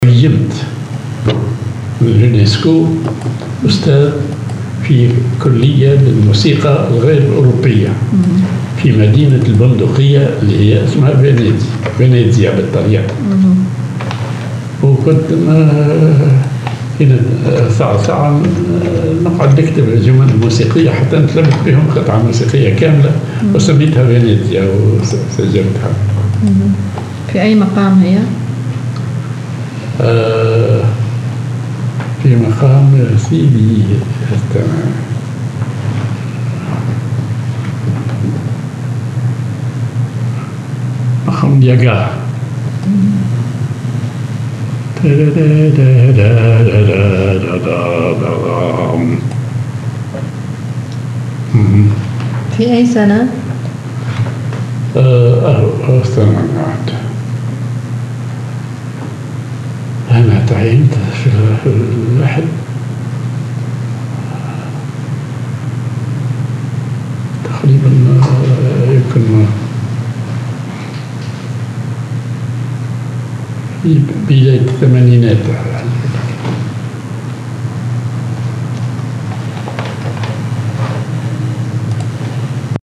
Maqam ar راست ياكاه وحجاز
معزوفة